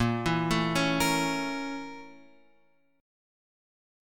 A#mM7b5 chord